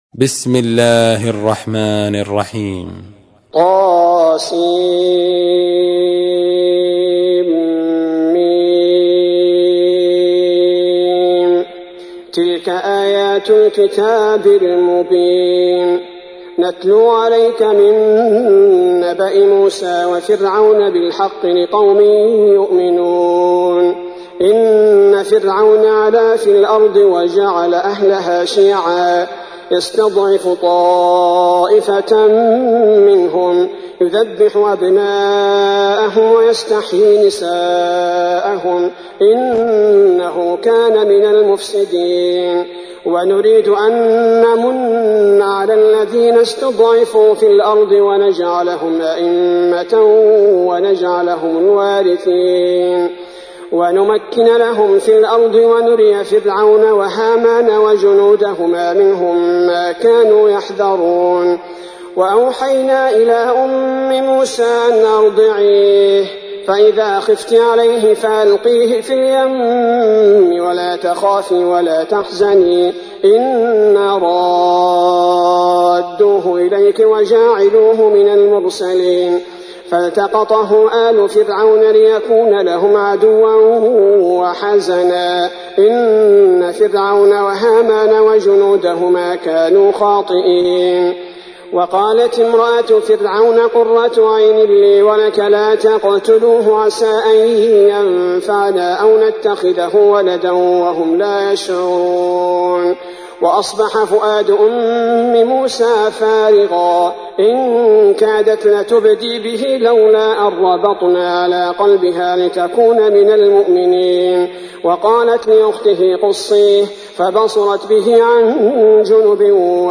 تحميل : 28. سورة القصص / القارئ عبد البارئ الثبيتي / القرآن الكريم / موقع يا حسين